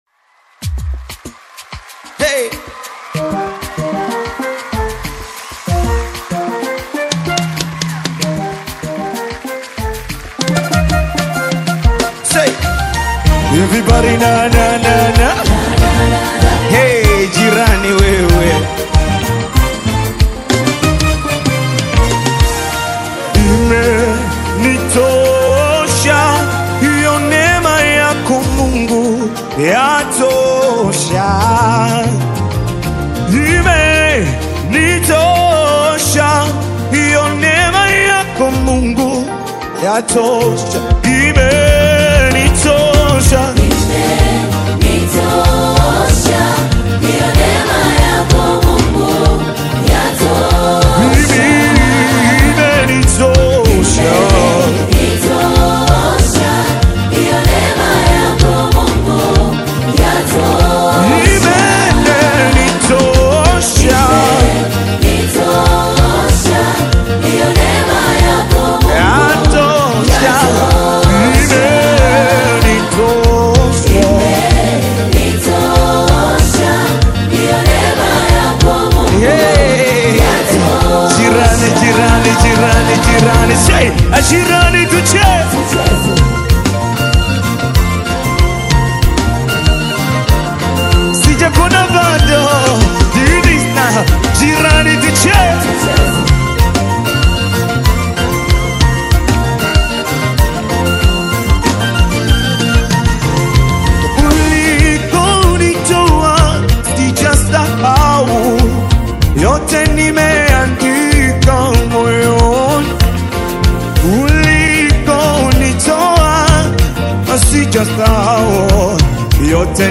soul-stirring and doctrinally rich single